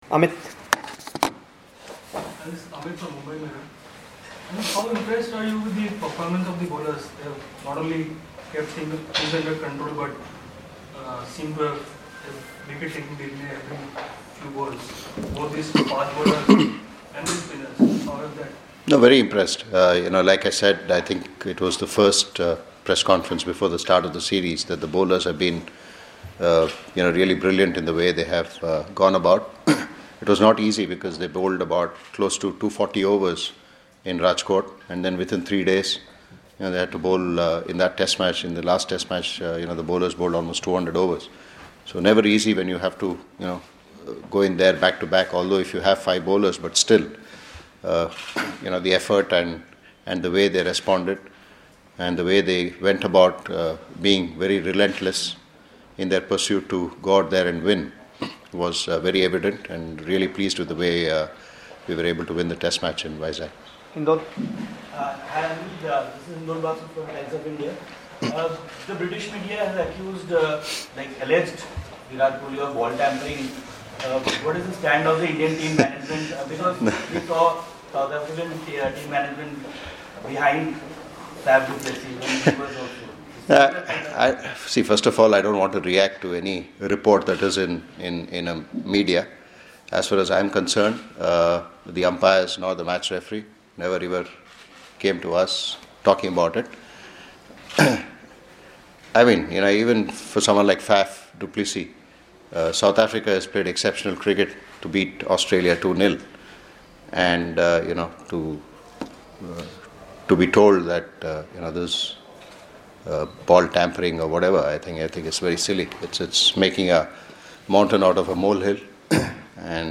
Anil Kumable at pre-match press confrence